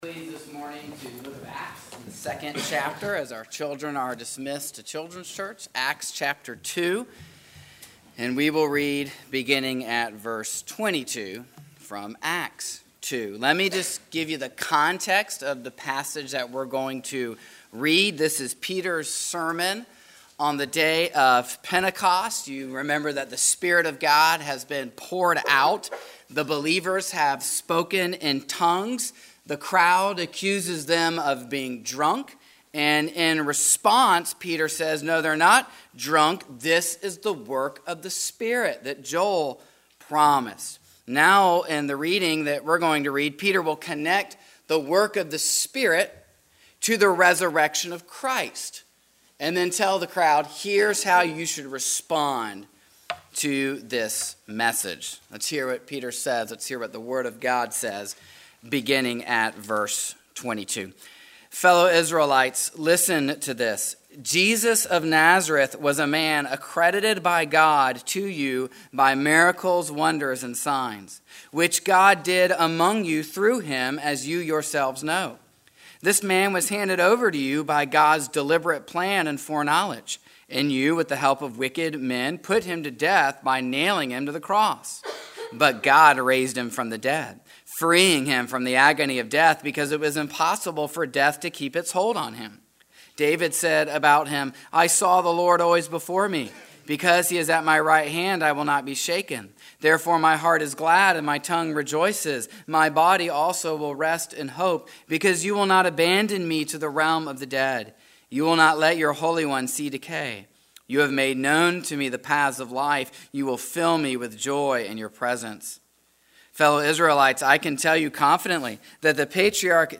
This sermon looks at two questions. What is baptism? And, how do we understand Acts 2:38 as we interpret scripture with scripture?